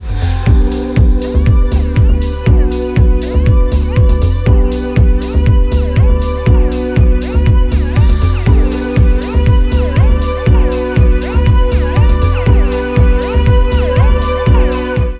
красивые
без слов
indie pop
Завораживающие
магические
космическая музыка